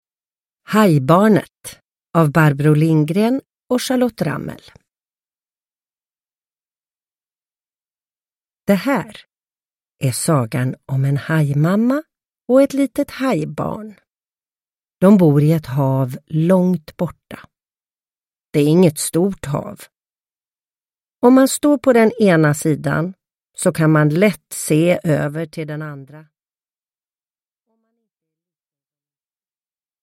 Hajbarnet – Ljudbok – Laddas ner